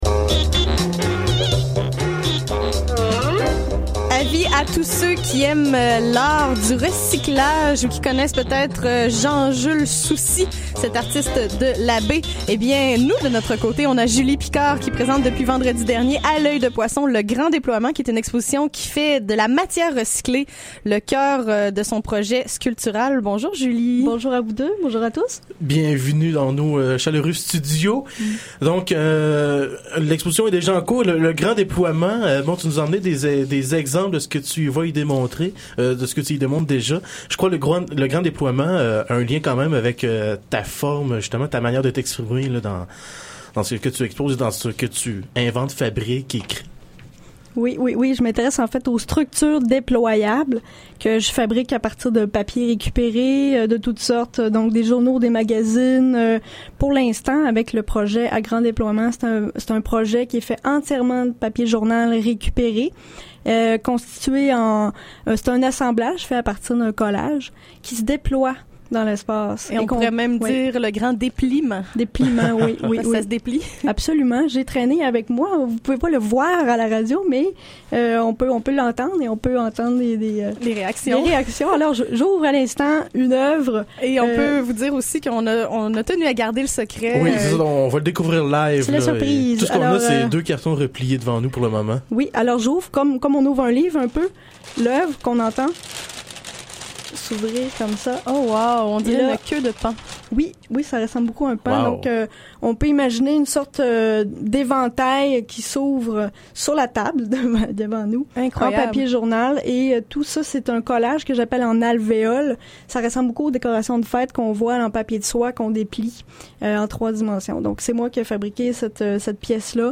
entrevue de 8 minutes en studio pour la chronique arts visuels CHYZ 94.3 Université Laval Animateurs